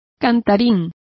Complete with pronunciation of the translation of singsong.